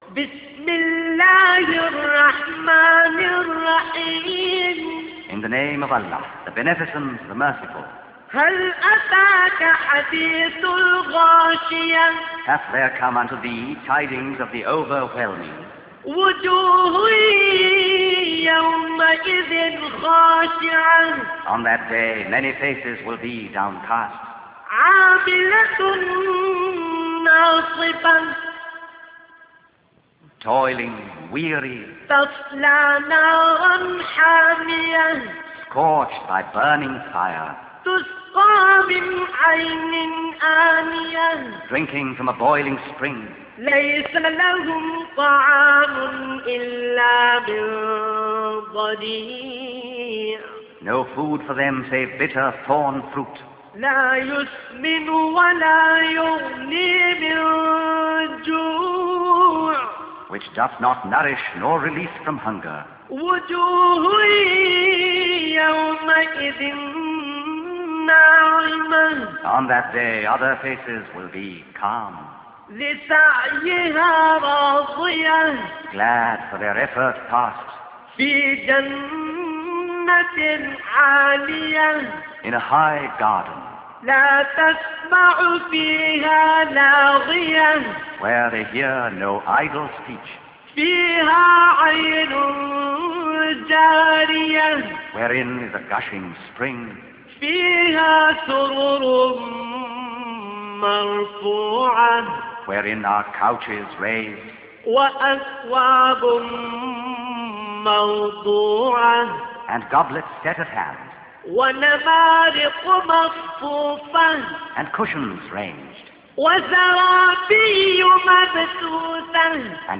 · Recitation of Quran